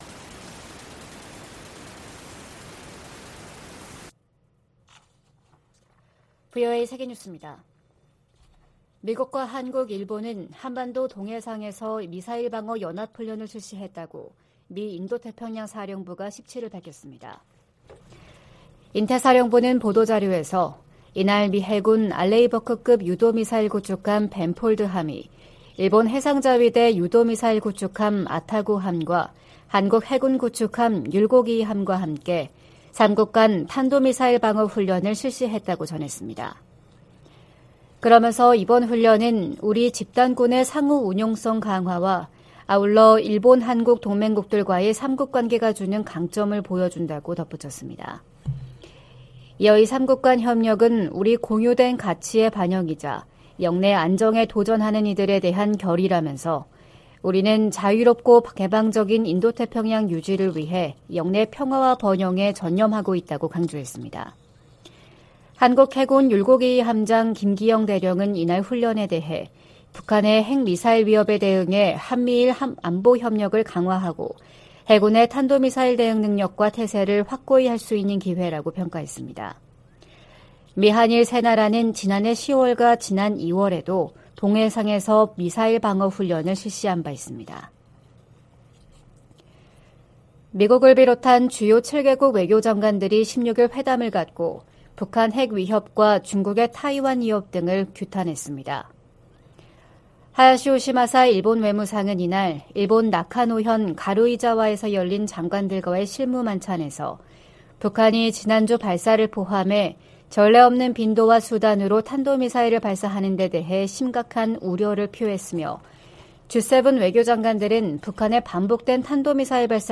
VOA 한국어 '출발 뉴스 쇼', 2023년 4월 18일 방송입니다. 한국과 일본의 외교·국방 당국이 참여하는 '2+2' 외교안보대화가 서울에서 5년만에 열렸습니다. 미한일이 3년 만에 안보회의를 개최하고 미사일 방어와 대잠수함전 훈련 정례화에 합의했습니다. 유엔 안보리가 17일, 북한의 대륙간탄도미사일(ICBM) 발사에 대응한 공개 회의를 개최합니다.